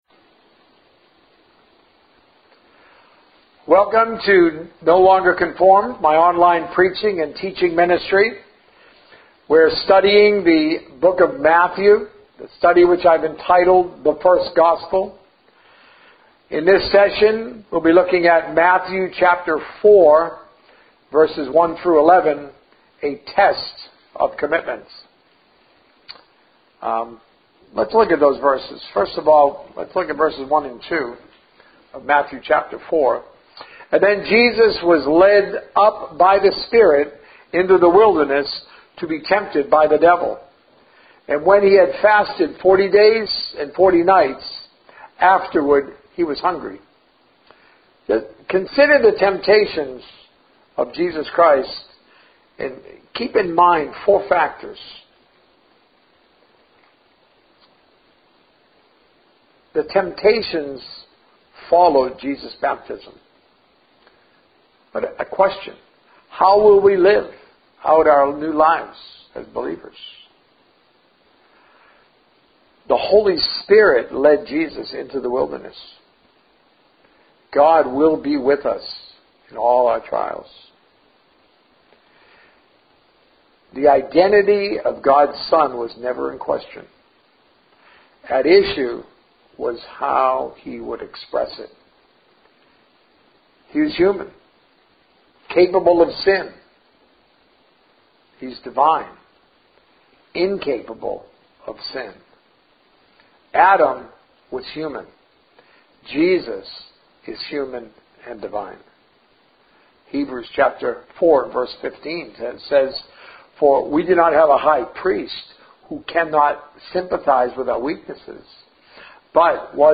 Sermons - No Longer Conformed